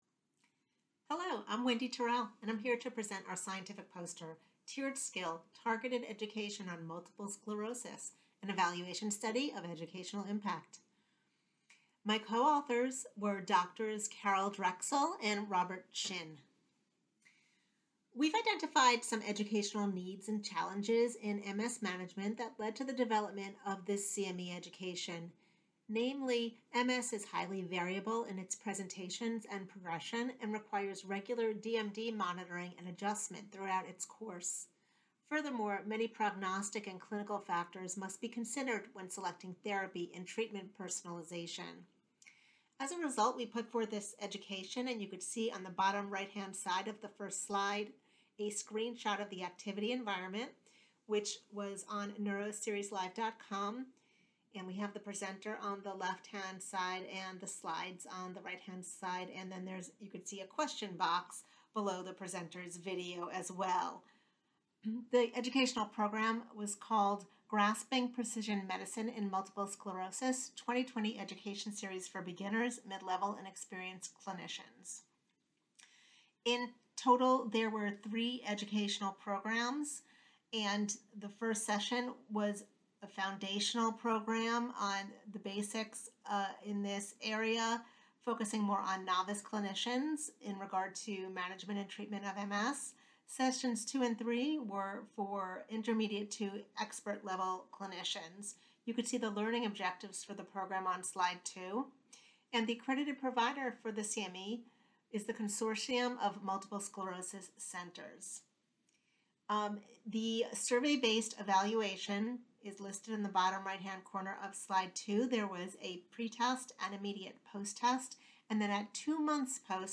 AAN-2021-MS-audio-presentation-MP3.mp3